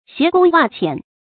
鞋弓襪淺 注音： ㄒㄧㄝ ˊ ㄍㄨㄙ ㄨㄚˋ ㄑㄧㄢˇ 讀音讀法： 意思解釋： 見「鞋弓襪小」。